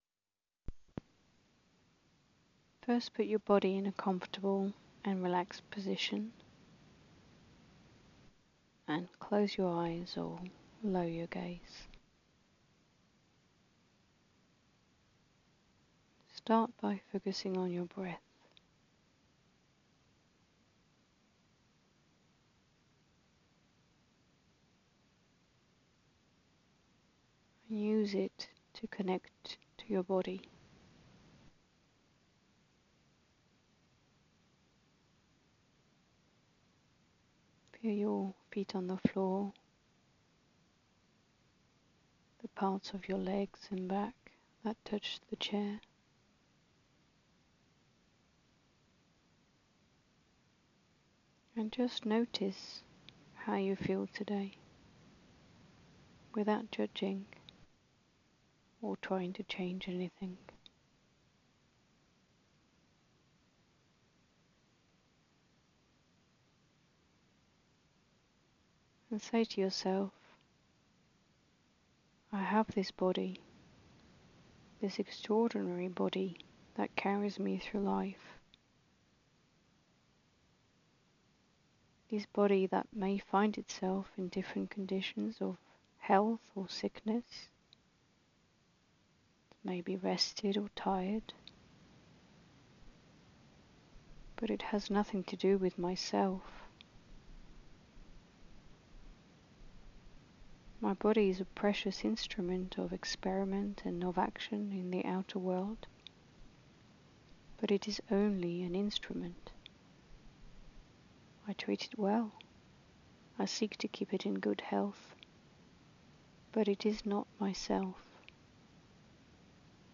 Self-identification meditation (9 min)